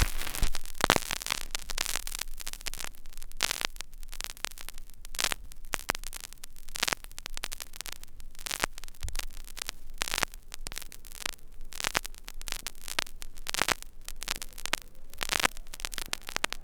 VINYL1    -R.wav